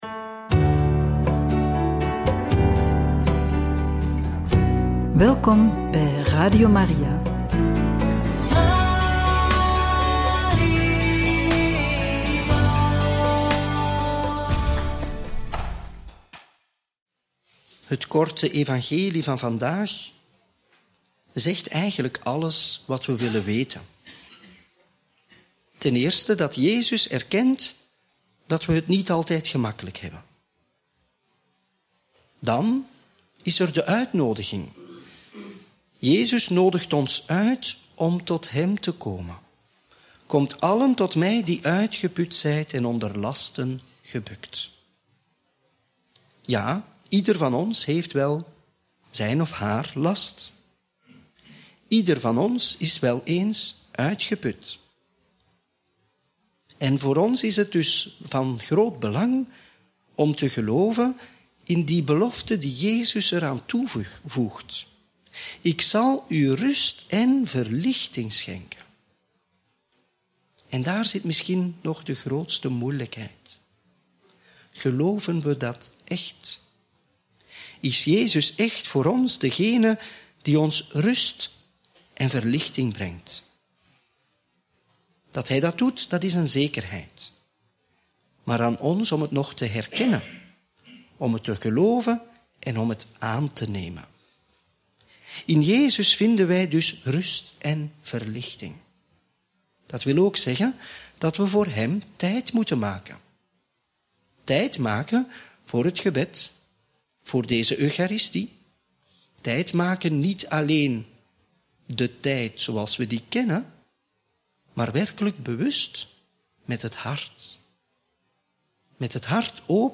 Homilie bij het Evangelie op donderdag 18 juli 2024 (Mt. 11, 28-30)